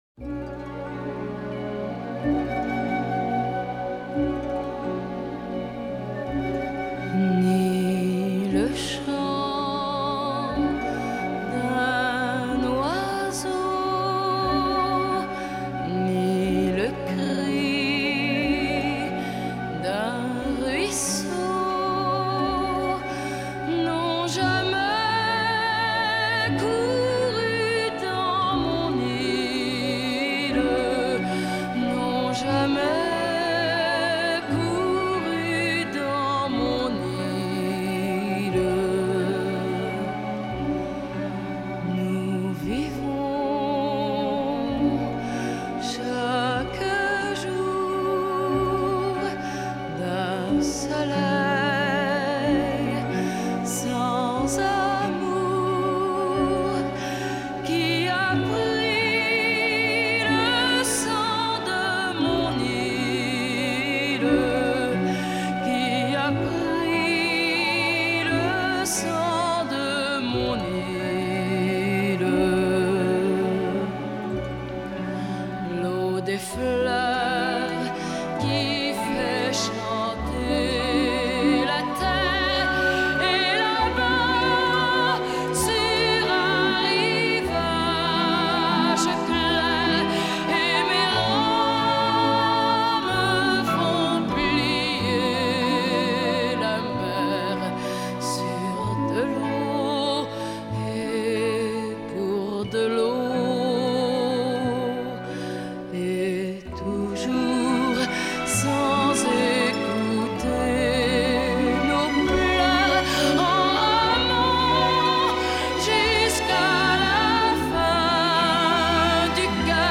chanson